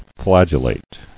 [flag·el·late]